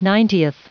Prononciation du mot ninetieth en anglais (fichier audio)